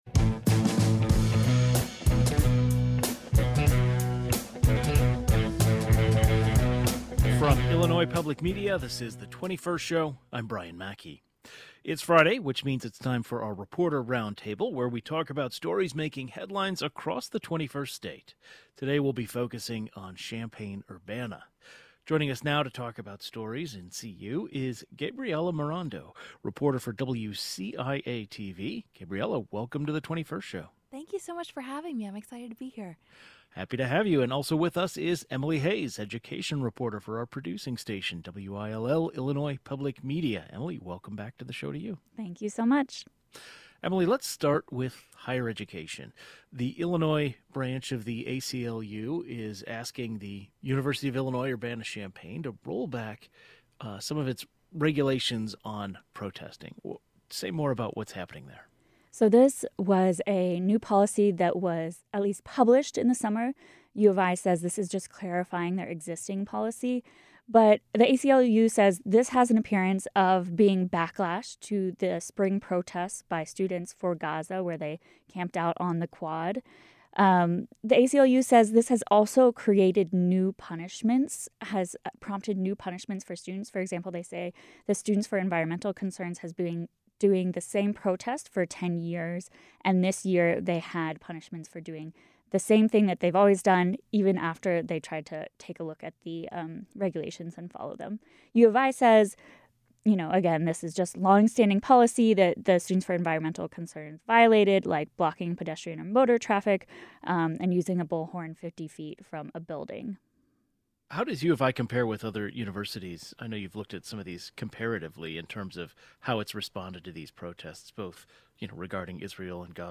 Our Friday reporter roundtable talks about stories making headlines in Champaign-Urbana, including the American Civil Liberties Union calling on the University of Illinois to rollback protesting regulations, as well as new Asian grocery market opening in Urbana.